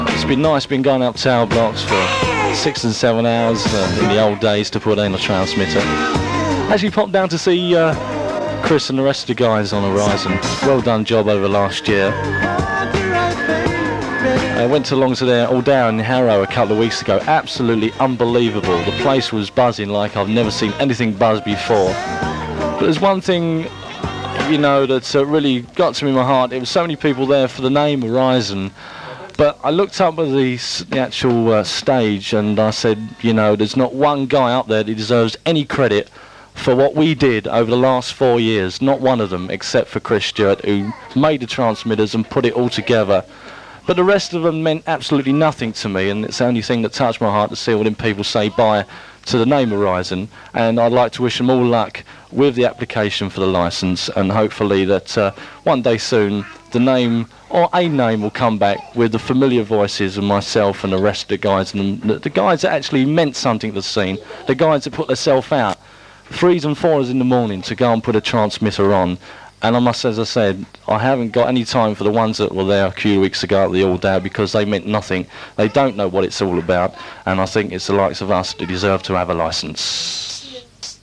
The broadcast quality of Solar Radio that day was so amateurish (hiss up in a brewery comes to mind) to say the least as studio microphones did not work half the time and the OB was a shambles.